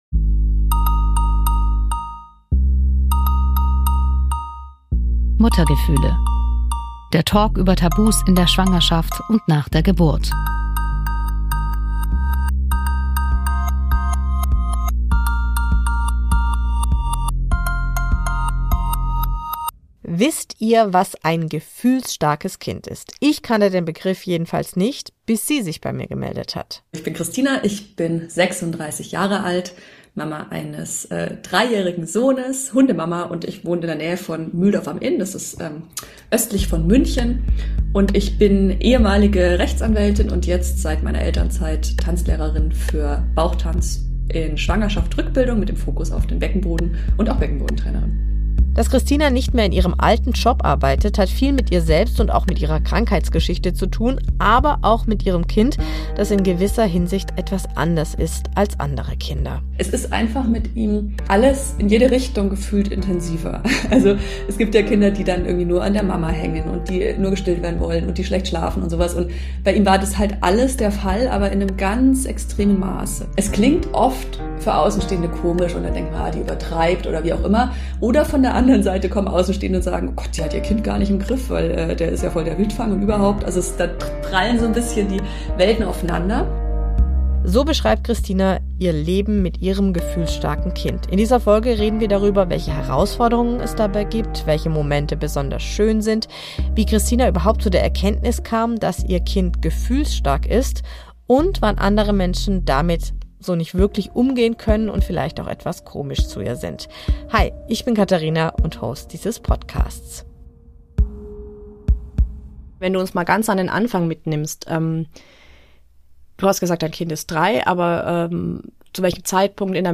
1 #219 - Kunsttherapie, Mutterschaft, Herausfordernde Zeiten - Interview